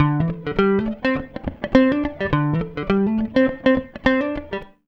104FUNKY 14.wav